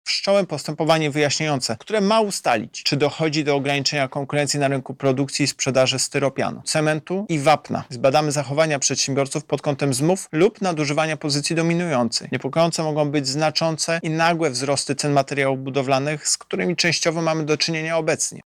• mówi prezez UOKiK Tomasz